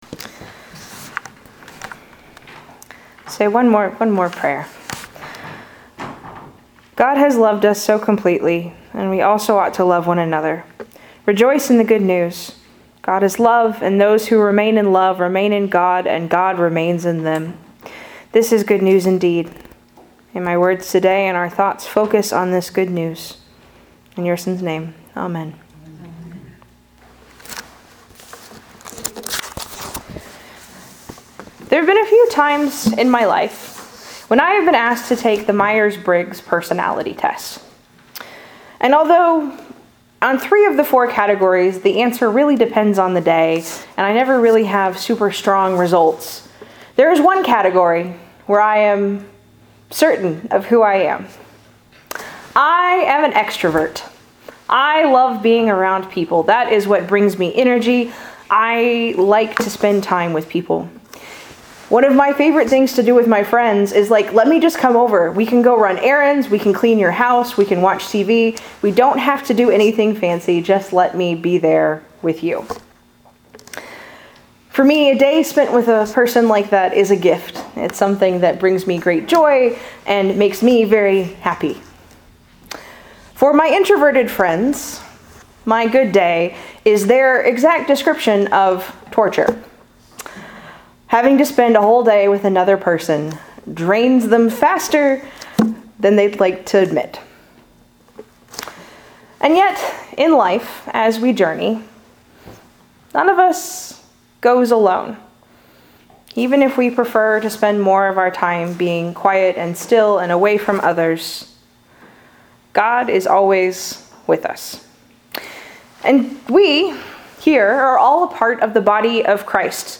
SermonMay2.MP3